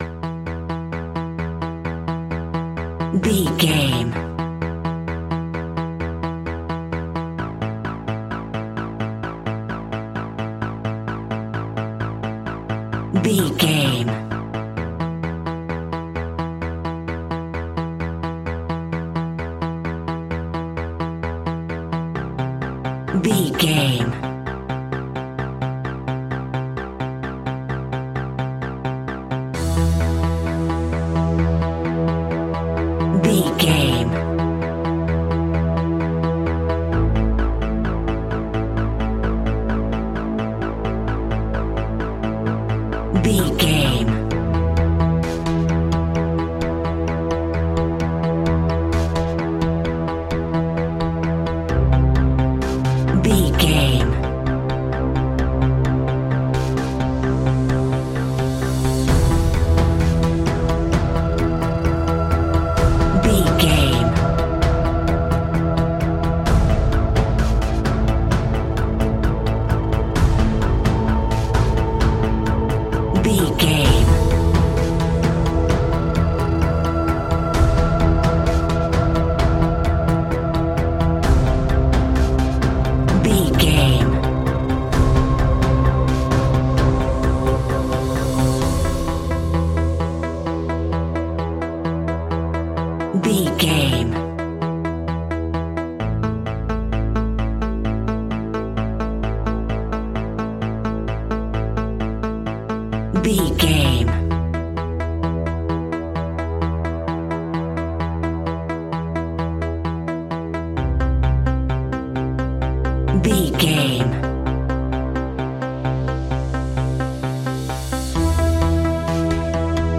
royalty free music
Aeolian/Minor
scary
ominous
dark
haunting
eerie
futuristic
synthesiser
drums
ticking
electronic music